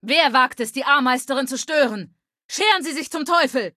Datei:Femaleadult01default ms02 greeting 000b2946.ogg